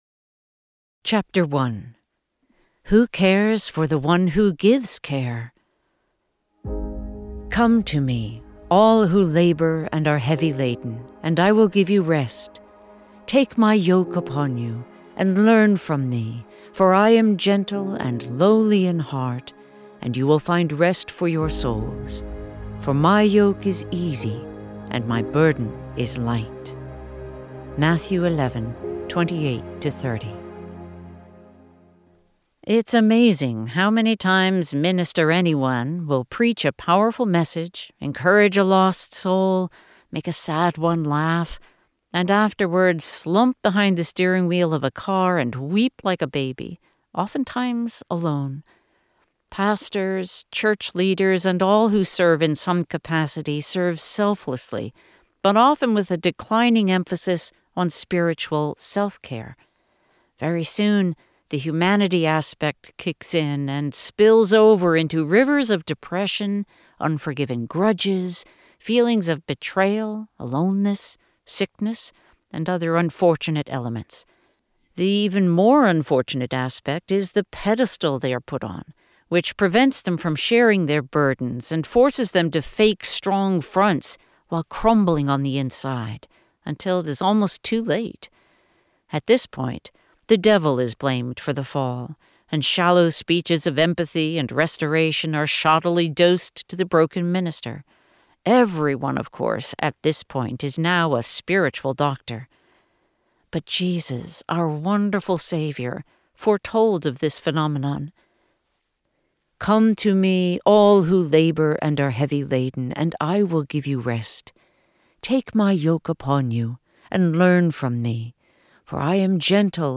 • Audiobook • 3 hrs, 34 mins